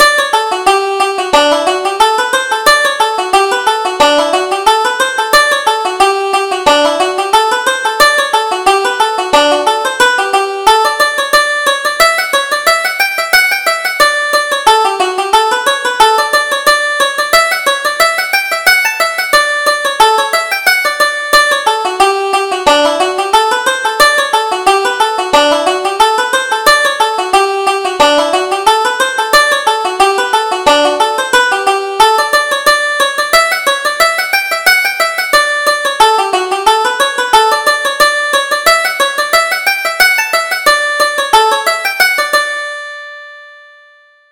Reel: On the River Bank